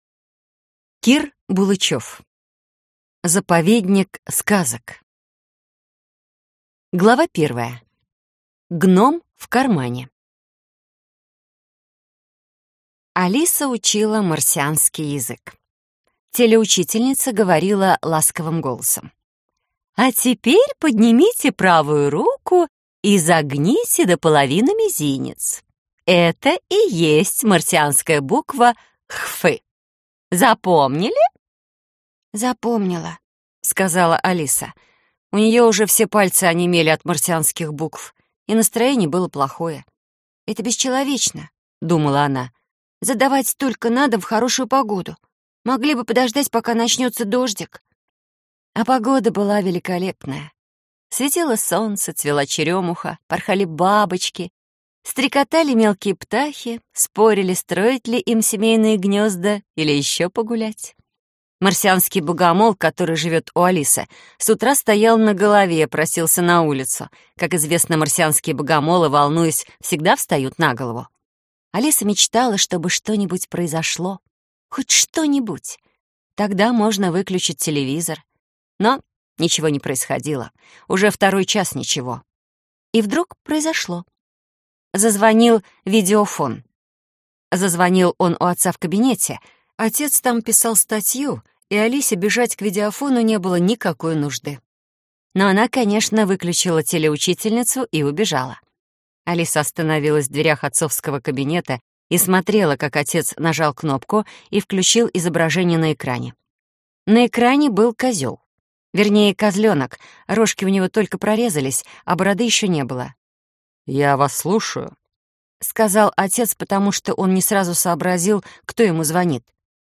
Аудиокнига Заповедник сказок | Библиотека аудиокниг